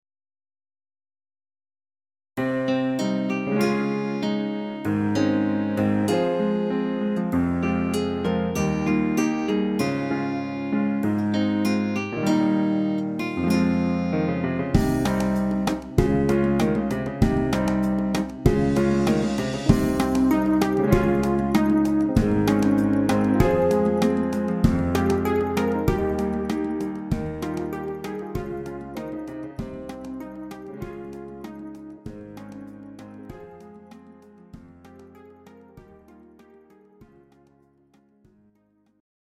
Greek Ballad